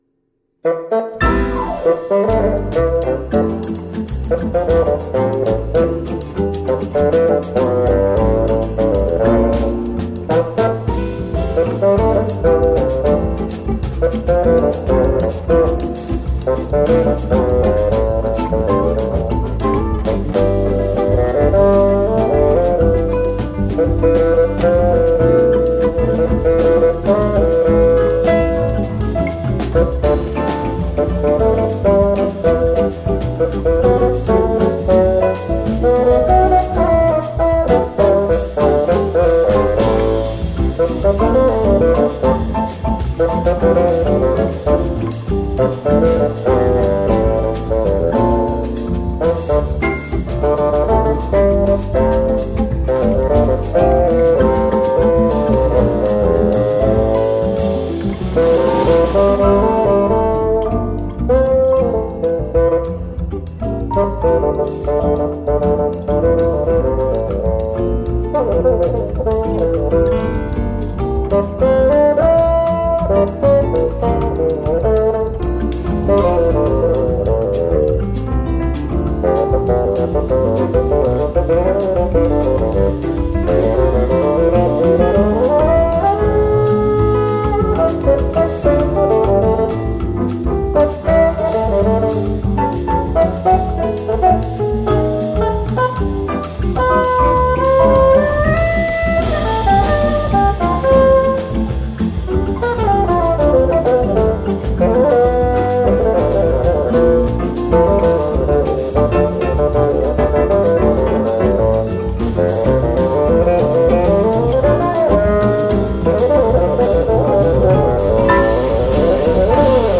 o CD de um fagote tocando jazz
• o fagote nunca deixa de soar como fagote,
• a versatilidade do ritmo contagia,